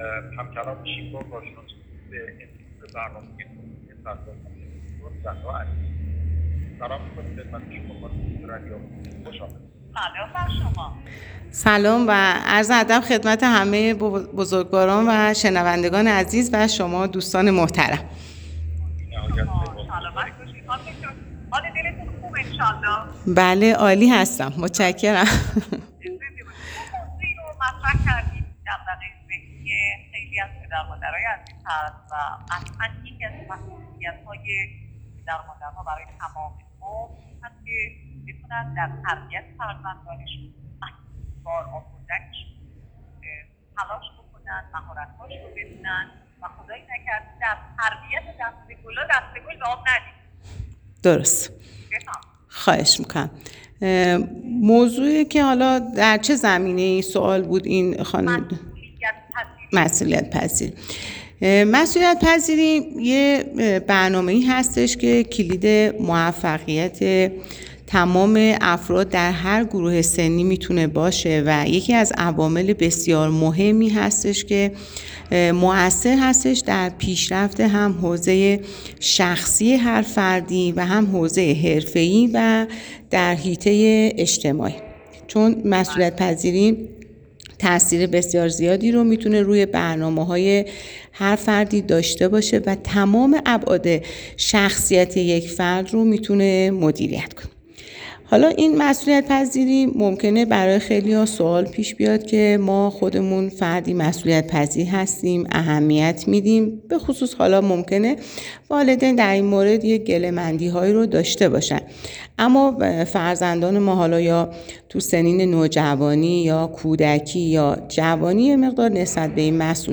/گفت و گوی رادیویی/